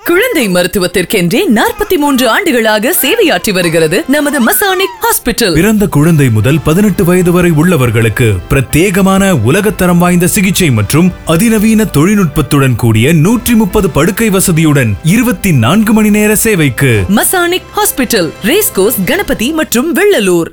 Masonic Hospital – Radio Commercial